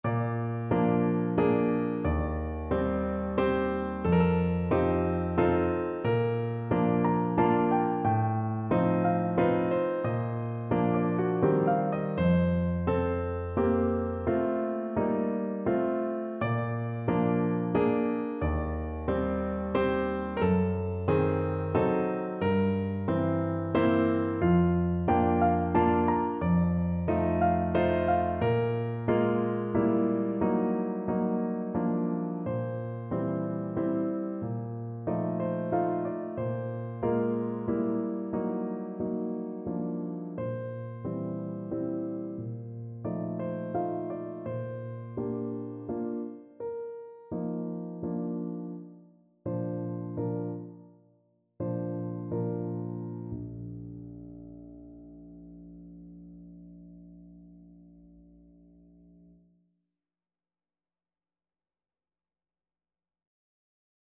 Free Sheet music for Piano
No parts available for this pieces as it is for solo piano.
6/8 (View more 6/8 Music)
Bb major (Sounding Pitch) (View more Bb major Music for Piano )
(Andantino) = 45 (View more music marked Andantino)
Piano  (View more Intermediate Piano Music)
Classical (View more Classical Piano Music)
chopin_cantabile_B.84_PNO.mp3